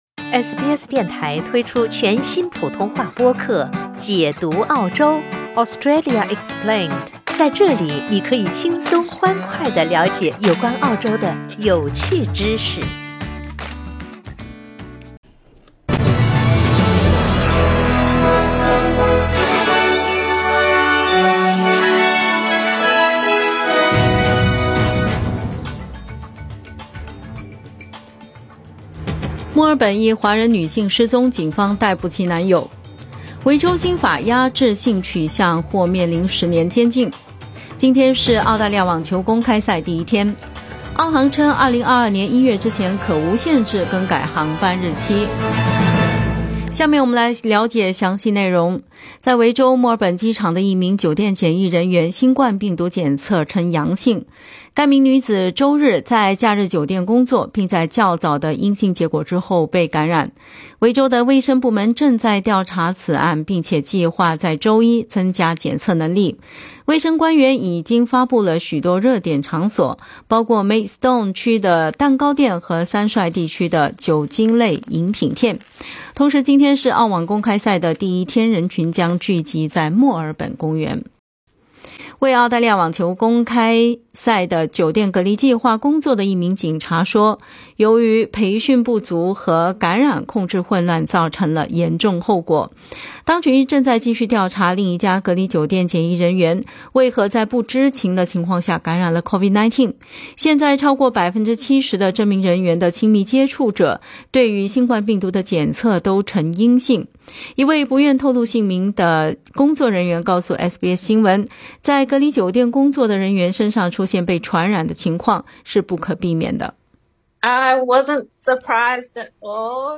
SBS早新聞（2月8日）